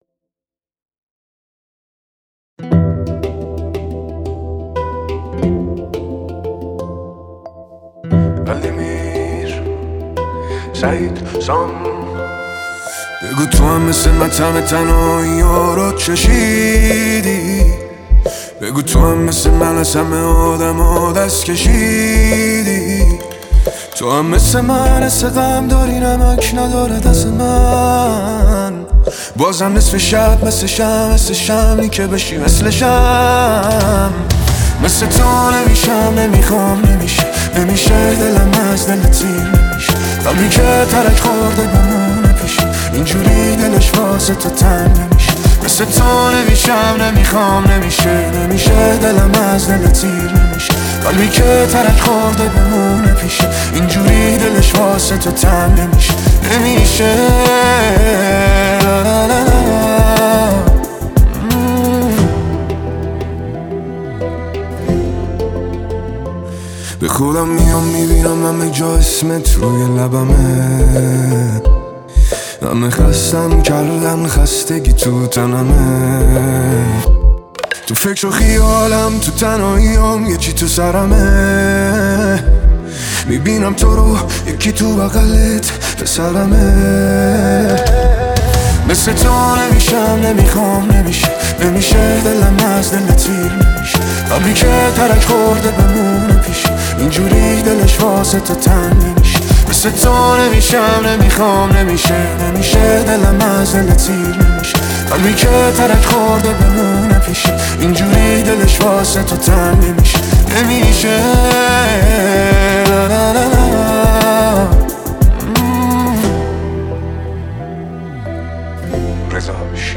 ریمیکس تند افزایش سرعت